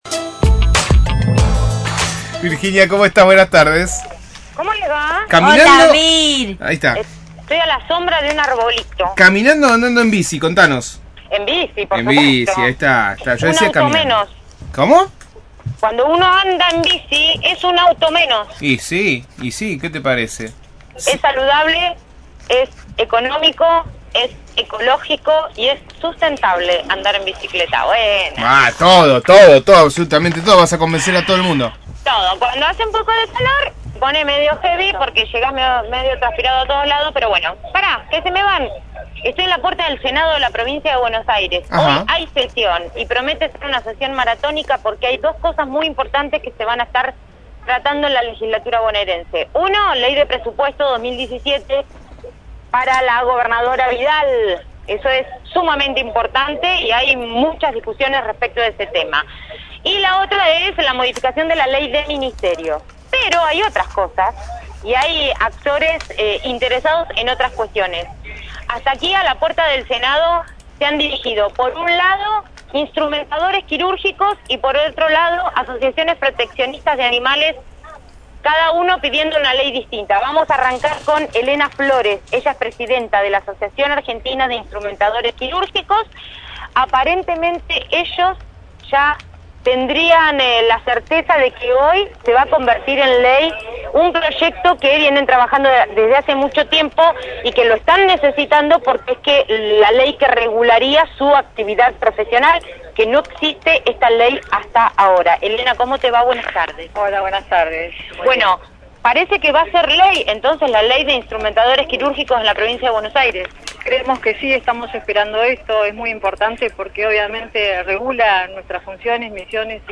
desde la Legislatura bonaerense